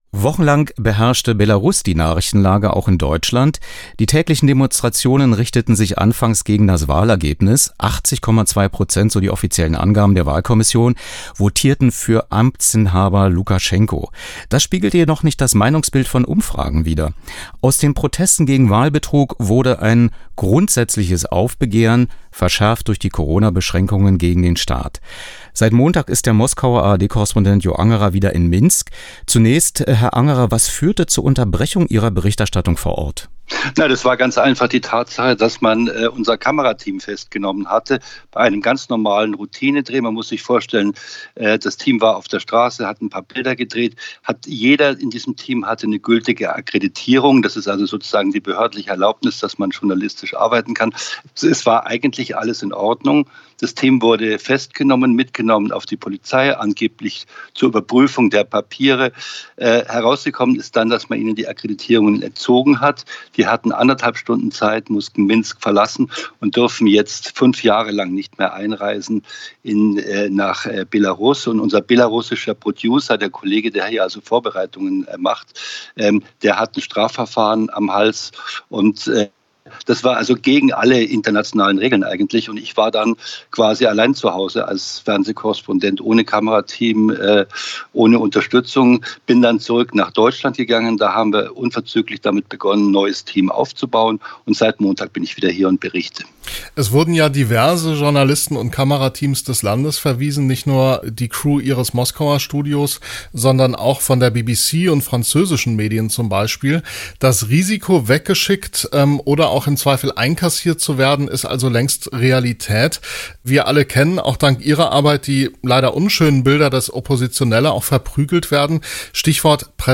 Freier Medienjournalist
Was: Skypegespräch über seinen Einsatz im Berichtsgebiet Belarus